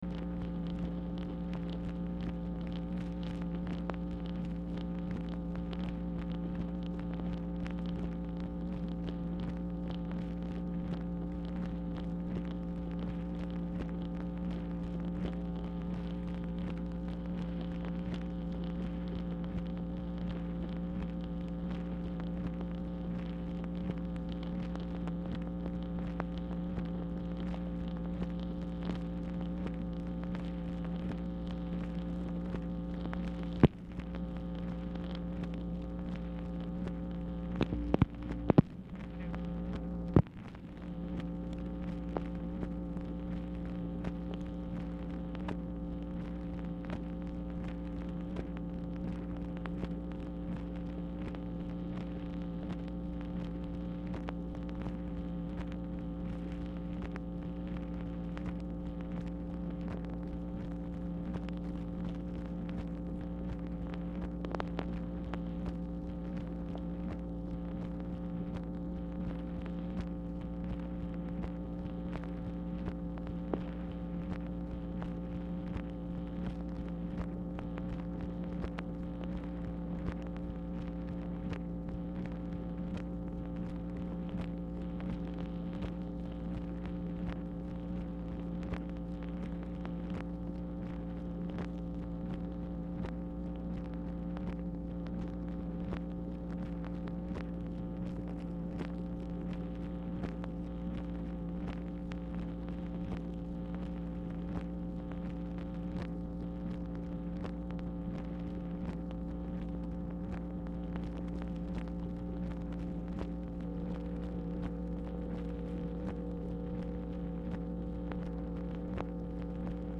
MACHINE NOISE
Format Dictation belt
Specific Item Type Telephone conversation